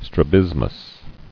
[stra·bis·mus]